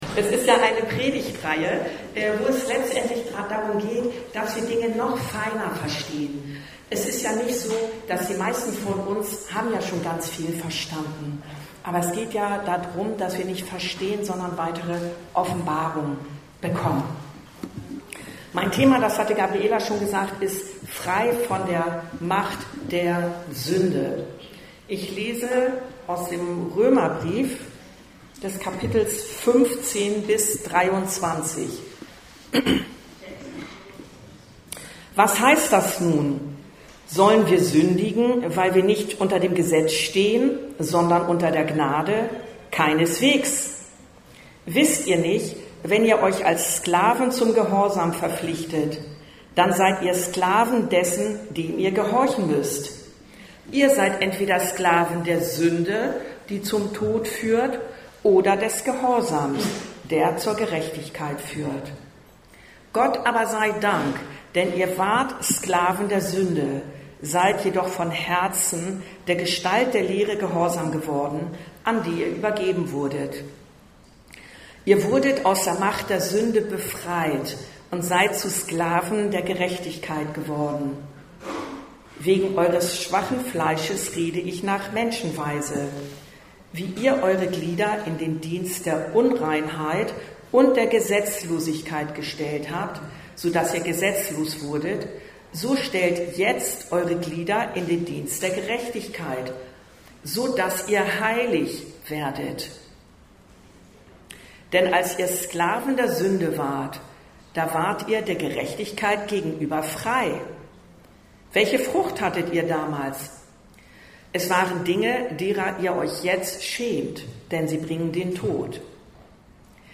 Wirklich frei… von der Macht der Sünde ~ Anskar-Kirche Hamburg- Predigten Podcast